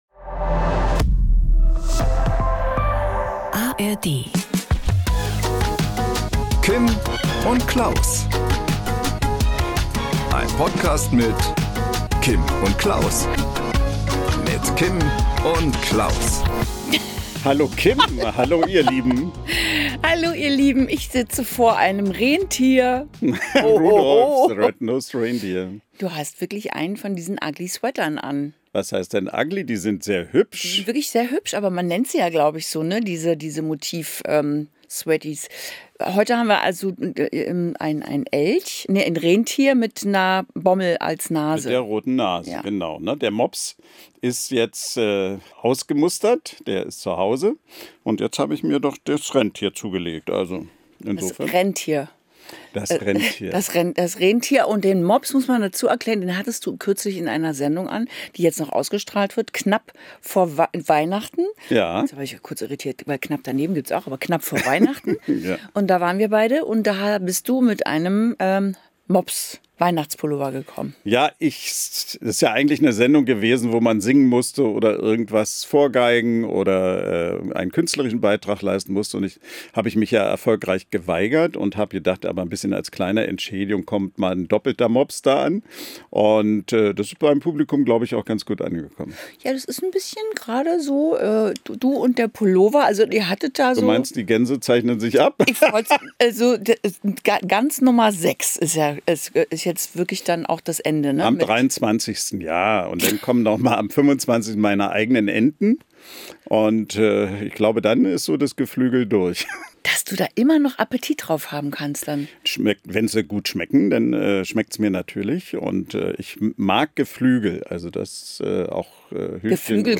Am Promi-Telefon: Pierre M. Krause. Der Moderator verrät, warum Bademäntel das absolut coolste Kleidungsstück sind.